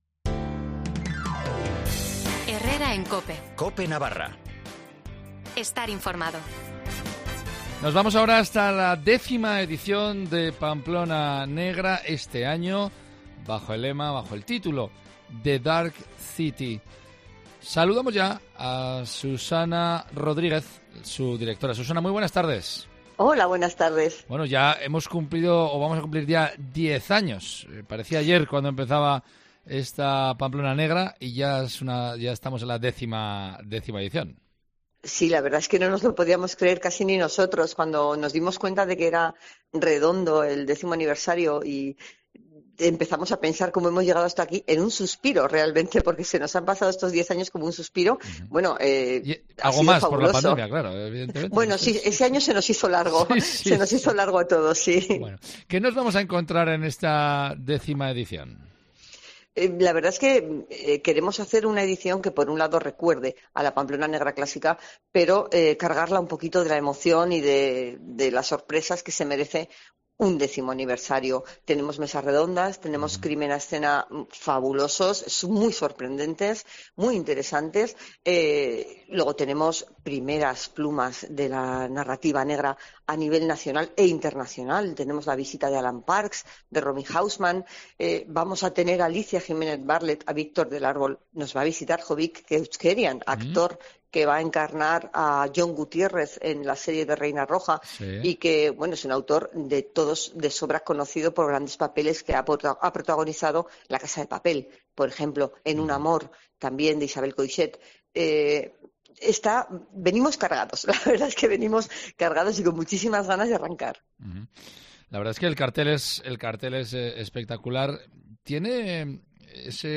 Ella ha pasado por los micrófonos de Cope Navarra para hablar del festival.